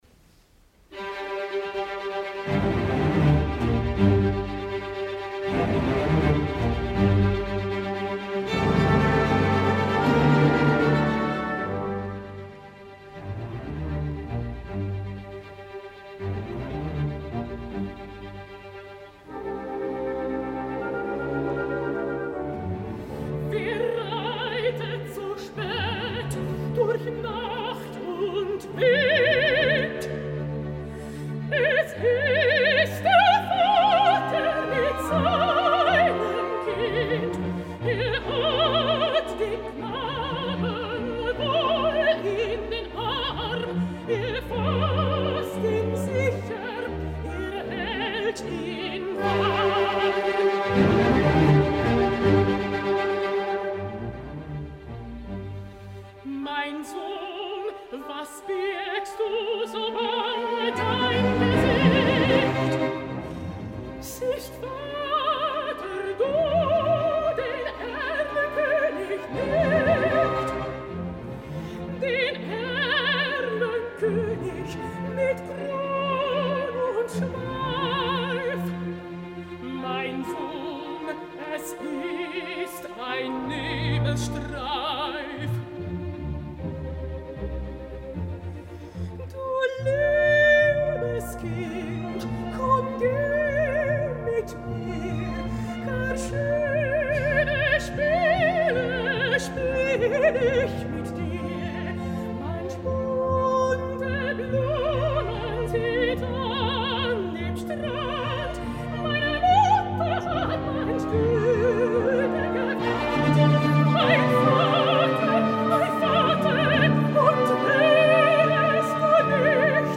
Aquest cap de setmana es reunien a la sala Philharmonie de Berlín, el director milanés, la jove mezzosoprano Christianne Stotijn que fa molt poc ja ens va visitar i un dels estels operístics del moment, el tenor Jonas Kaufmann, declarat per la revista Opernwelt, millor cantant de l’any, cosa que estic segur que molts compartim.
El concert s’iniciava amb tres lieders coneguts i bellíssims de Franz Schubert, en les versions orquestrades per Max Reger i Hector Berlioz, interpretada per la jove mezzosoprano holandesa Christianne Stotjin, que el dia 29 d’abril visitava In Fernem Land cantant l’Hymne an die nacht d’Alphons Diepenbrock.
(arranjament per a veu i orquestra d’Hector Berlioz)
Escoltem aquest últim lieder, Erlköning, en la meravellosa, subjugant i climàtica orquestració de Berlioz